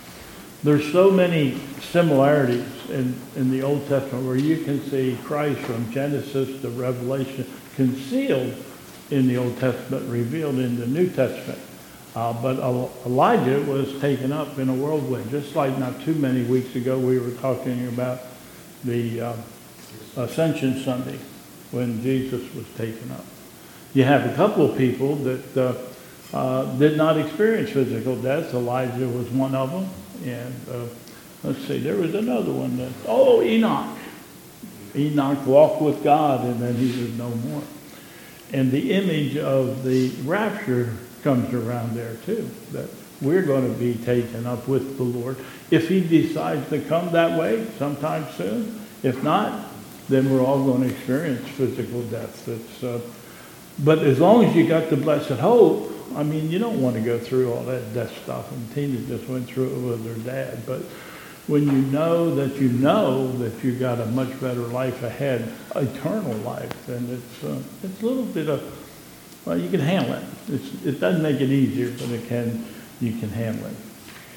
2022 Bethel Covid Time Service
Prayer Concerns